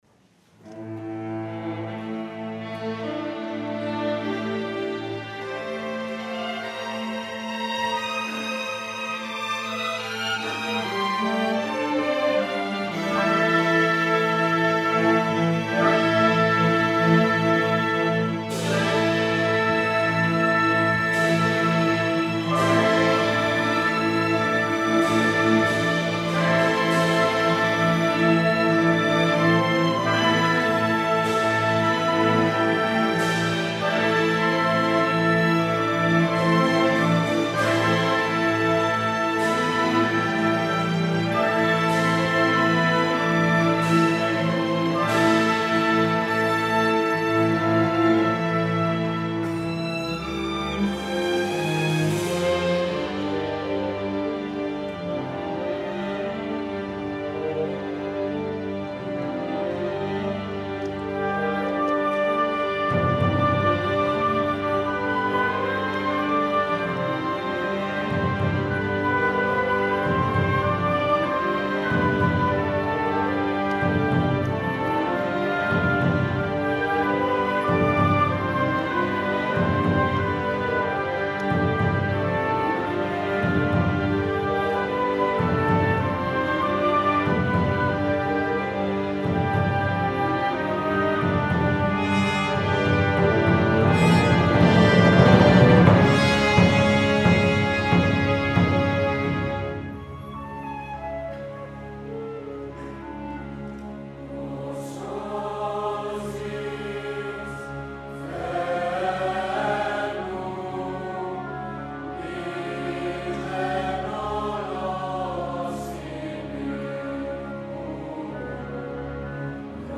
Seikilos Epitaph – for Choir and Orchestra
Cyprus Symphony Orchestra and Choirs from the High Scools of Larnaka and Famagusta